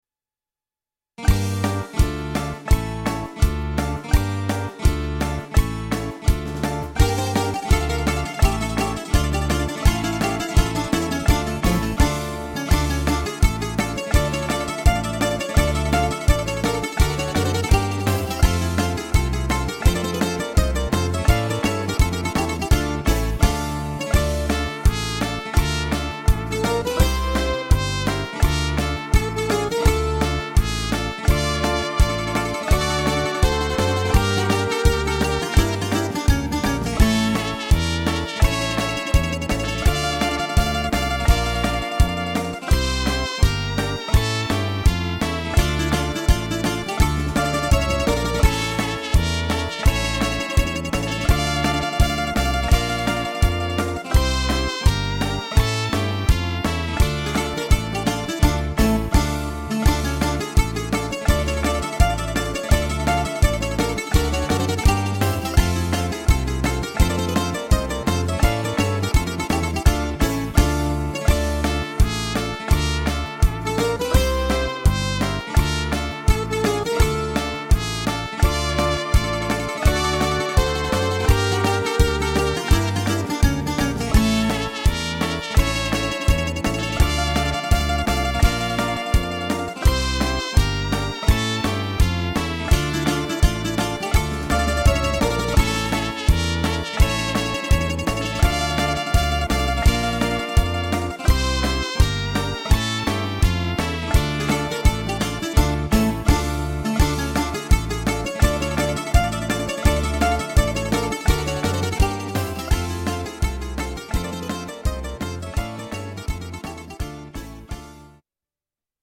Greek Dance Hasaposerviko-Serviko-2/4